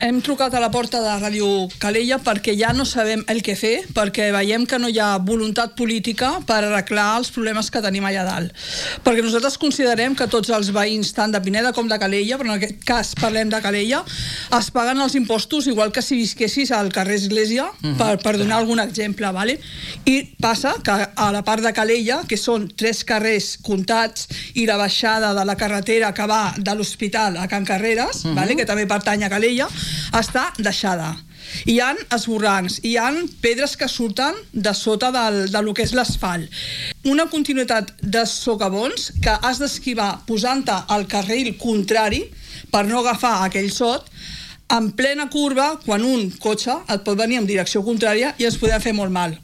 L’associació veïnal ha anunciat en una entrevista a RCT que, en les pròximes setmanes, presentarà un recurs contenciós administratiu per tal que el consistori reconegui la recepció tàcita de la urbanització i assumeixi el manteniment dels serveis bàsics, com ara l’asfaltatge, la neteja viària i l’enllumenat públic.